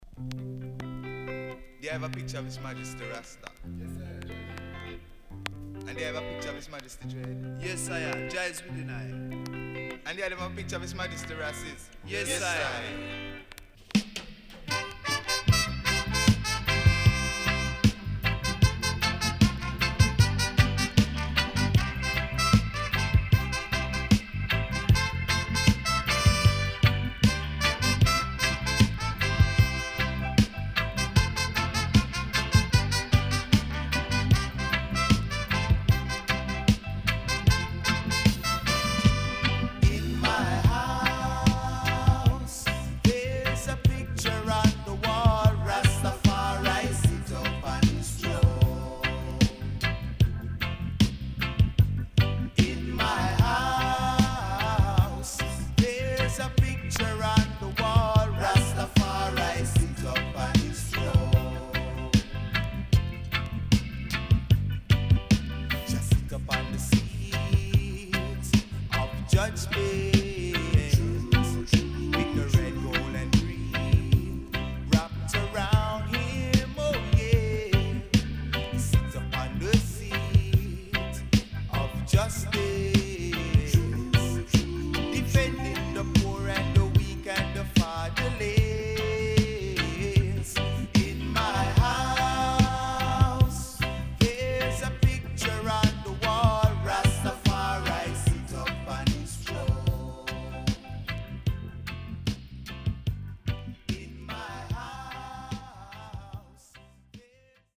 UK Great Roots
SIDE B:少しチリノイズ入ります。